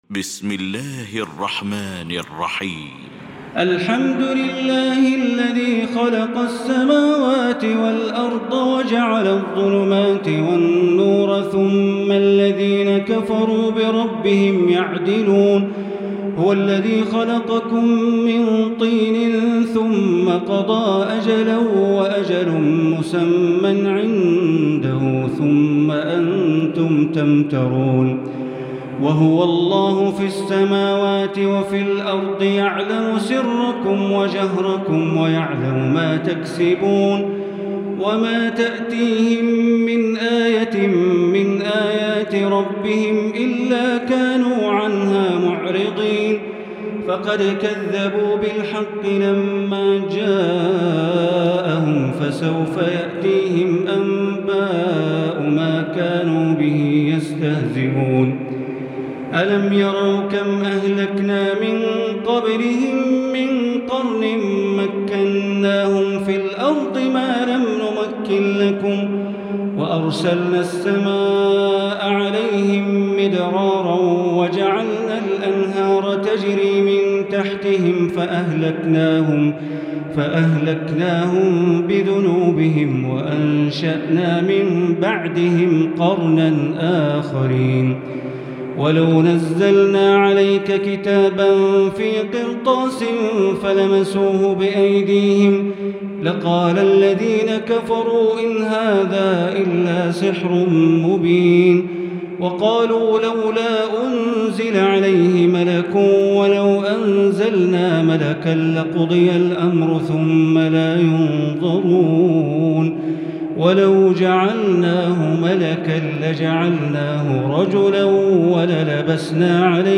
المكان: المسجد الحرام الشيخ: معالي الشيخ أ.د. بندر بليلة معالي الشيخ أ.د. بندر بليلة سعود الشريم معالي الشيخ أ.د. عبدالرحمن بن عبدالعزيز السديس فضيلة الشيخ ياسر الدوسري الأنعام